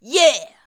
YEAH 3.wav